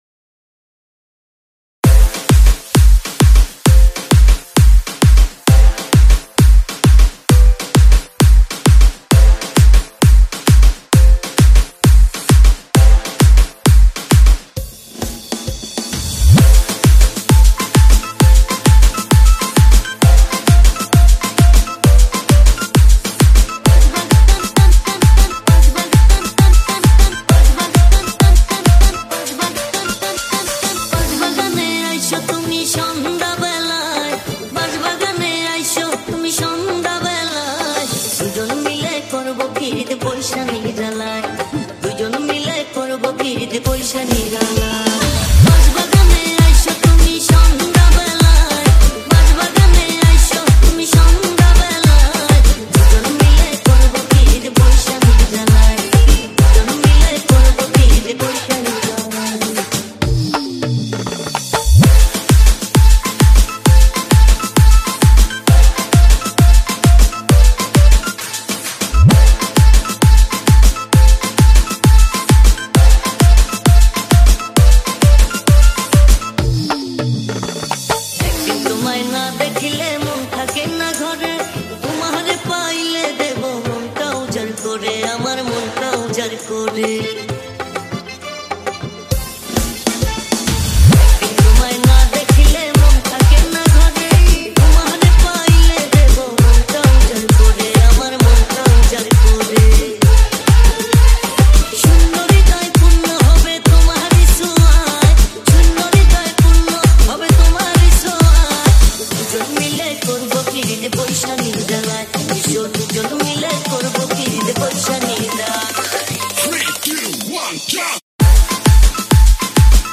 Category : Bangla Remix Song